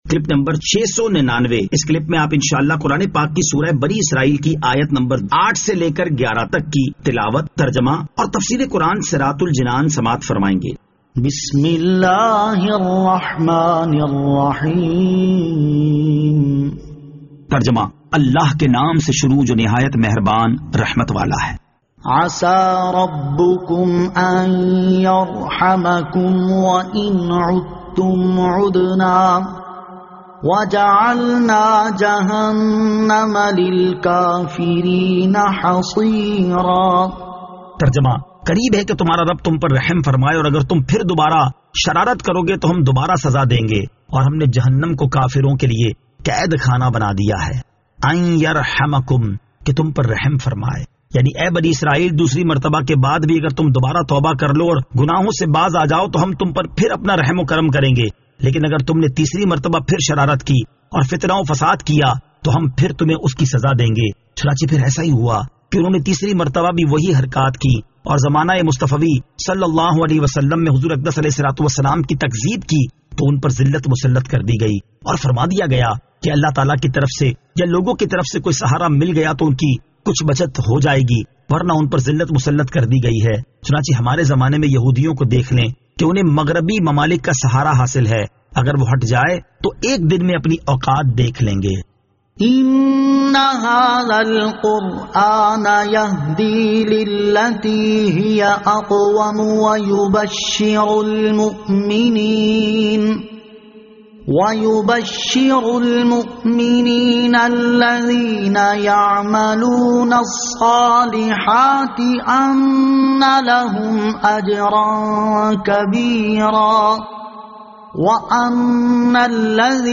Surah Al-Isra Ayat 08 To 11 Tilawat , Tarjama , Tafseer